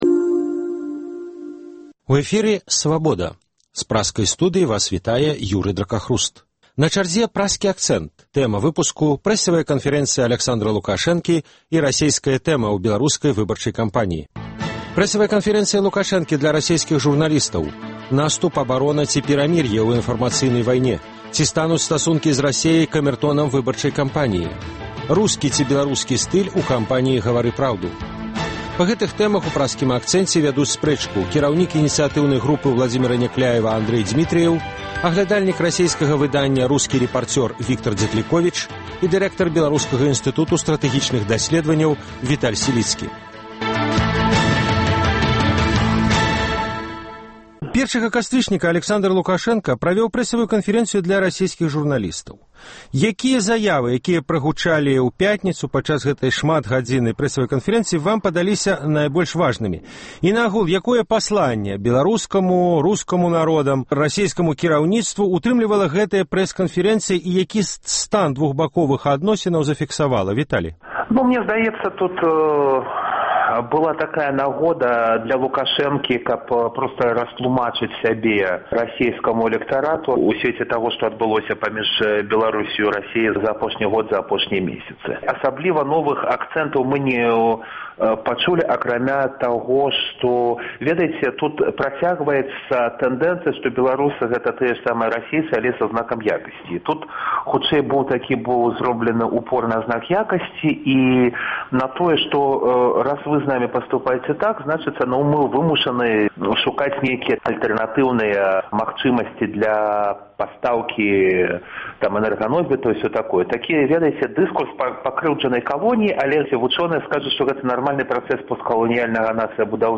Ці стануць стасункі з Расеяй камэртонам выбарчай кампаніі? У дыскусіі